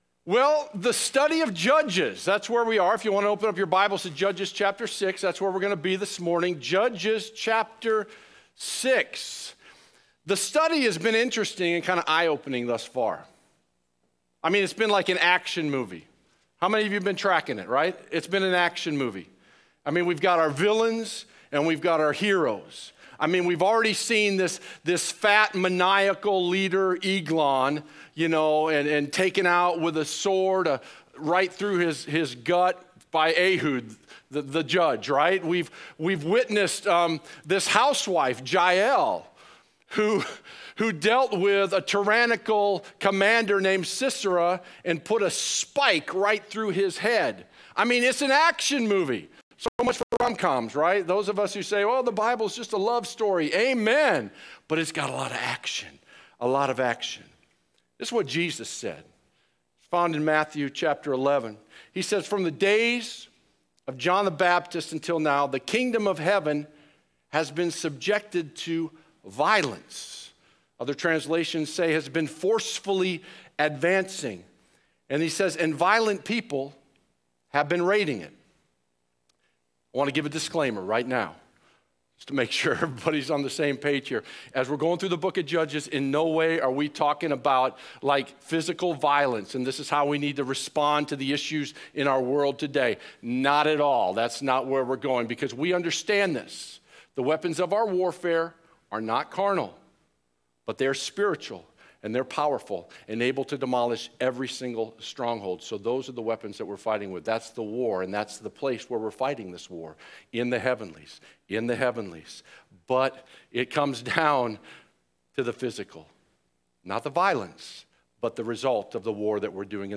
Sermon Archive (2016-2022) - Evident Life Church | Gospel-Centered, Spirit Filled Church in Gilbert, AZ